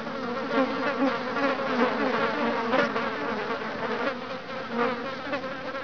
flies.wav